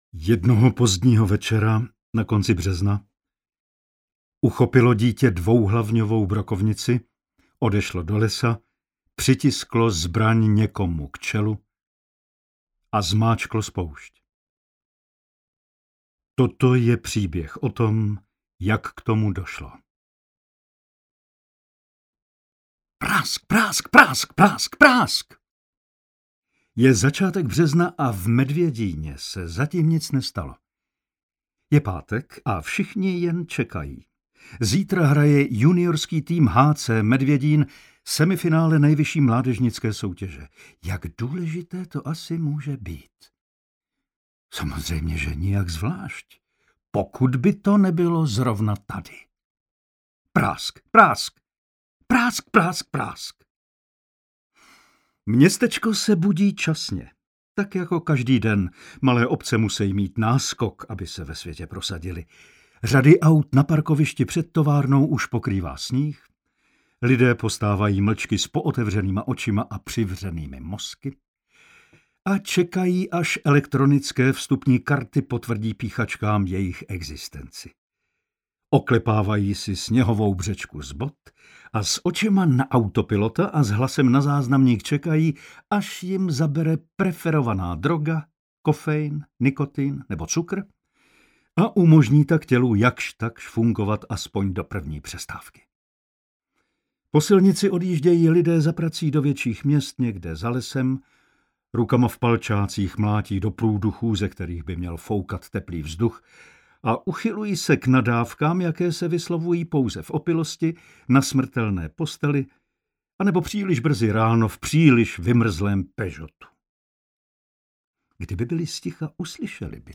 Medvědín audiokniha
Ukázka z knihy